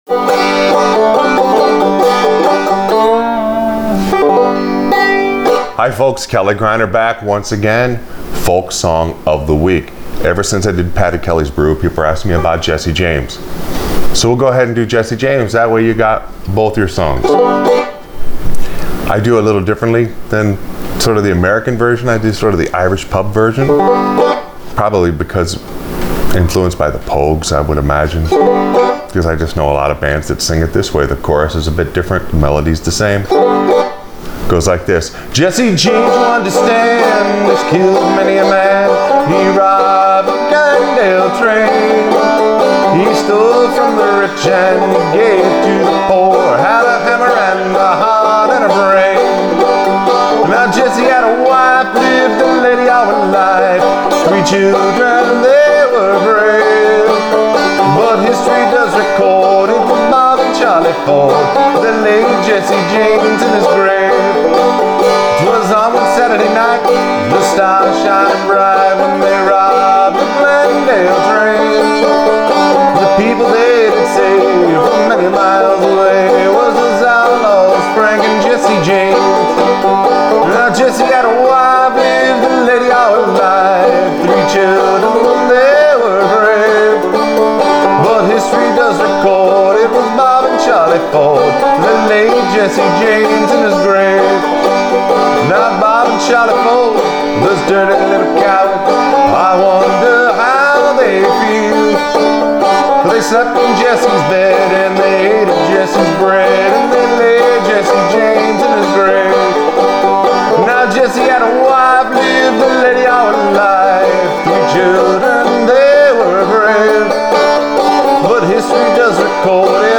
Folk Song Of The Week – Jesse James on Frailing Banjo
Grab your banjo and start frailing along!